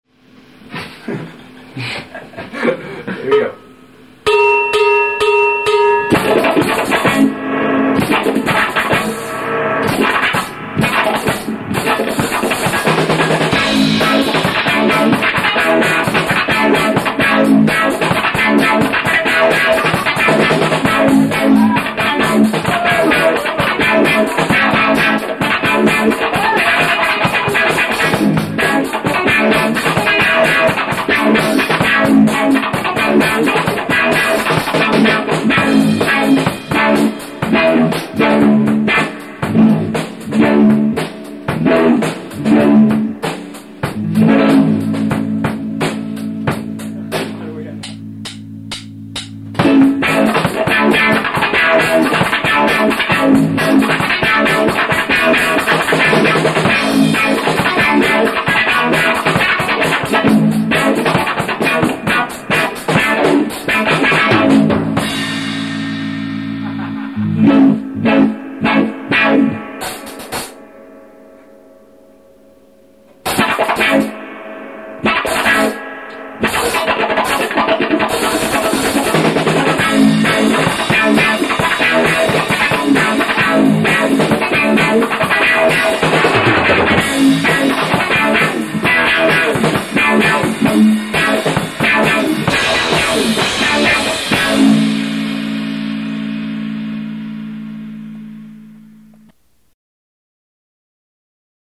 They are completely and unapologetically live.
It's funky as hell
wah-wah